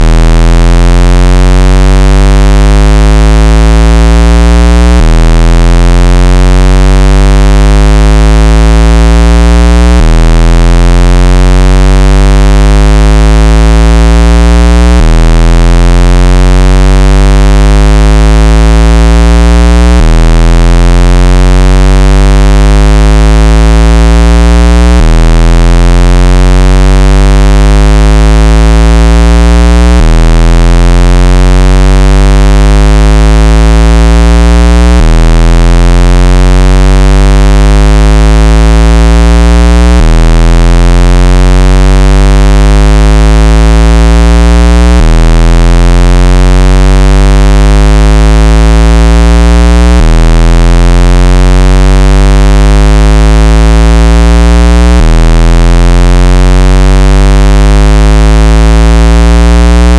• Bước 2: Dùng âm thanh có tần số thấp sau =>
Sound for Speaker Cleaner 40-60Hz.
sound-for-speaker-cleaner-40-60hz.mp3